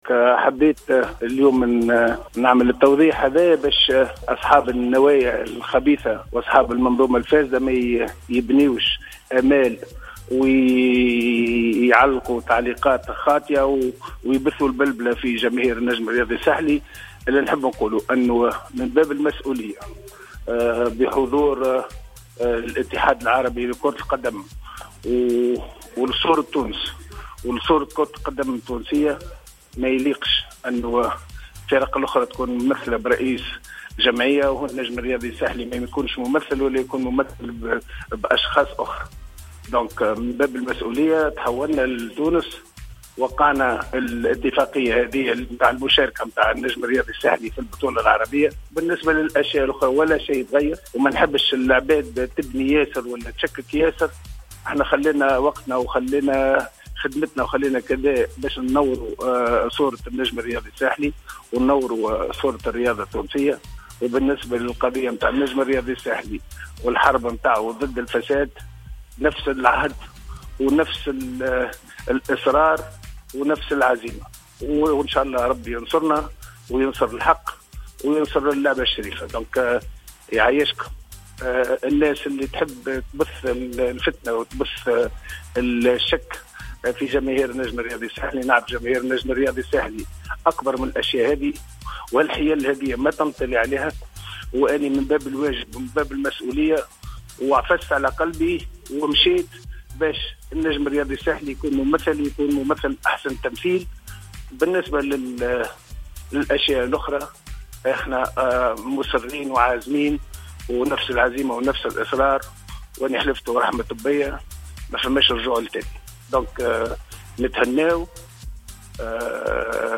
أوضح رئيس النجم الساحلي الدكتور رضا شرف الدين في تصريح لجوهرة أف أم أن حضوره اليوم الخميس 15 مارس 2018 في حفل توقيع بروتكول مشاركة النجم الساحلي في مسابقة البطولة العربية كان من باب المسؤولية و الإحترام لصورة تونس و لأعضاء الإتحاد العربي لكرة القدم.